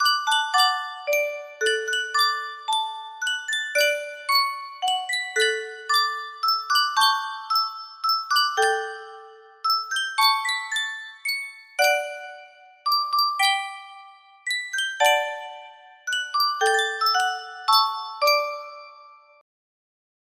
Reuge Music Box - Brahms Lullaby 43 music box melody
Full range 60